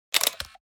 camera_click.mp3